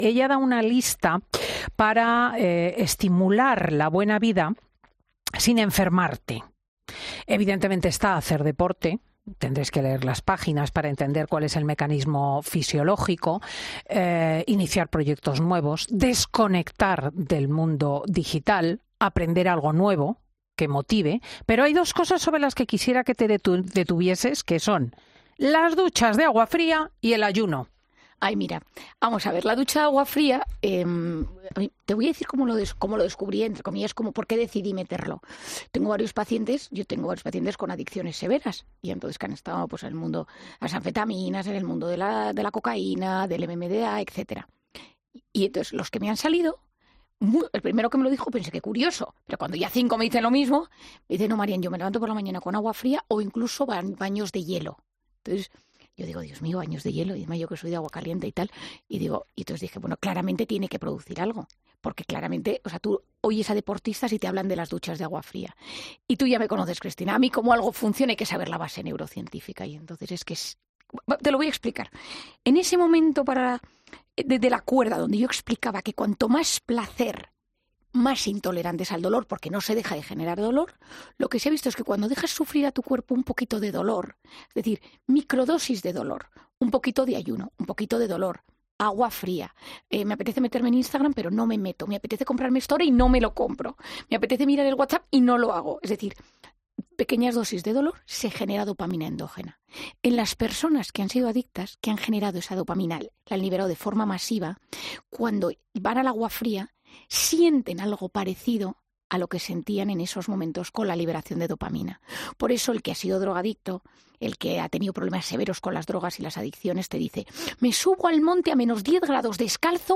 La psiquiatra ha pasado por los micrófonos de 'Fin de Semana' para presentar su nuevo libro 'Recupera tu mente, reconquista tu vida'
¿Qué hábitos de nuestra rutina pueden ayudarnos a cuidar nuestra salud? Marian Rojas le ha explicado a Cristina López Schlichting la lista que ella recomienda para "estimular la buena vida sin enfermar".